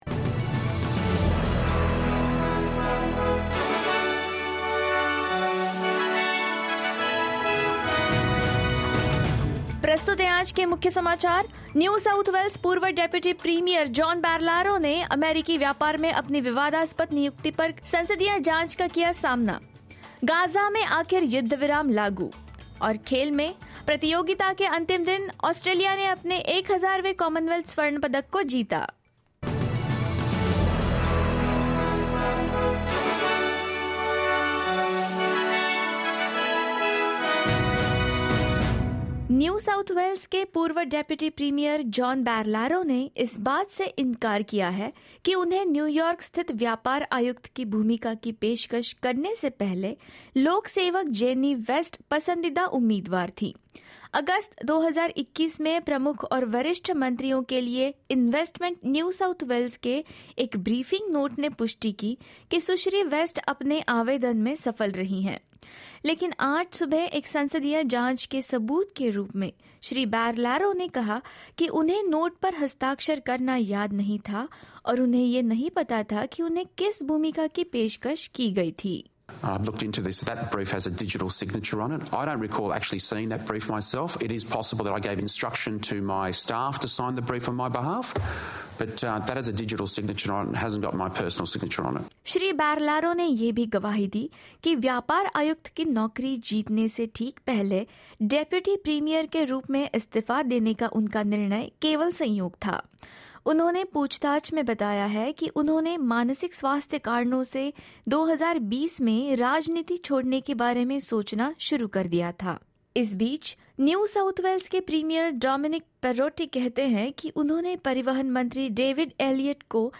In this latest SBS Hindi bulletin: John Barilaro rejects suggestions that he pushed another candidate aside for U-S trade job; A ceasefire comes into effect in Gaza; Australia claims its 1,000th gold medal at Commonwealth Games and more.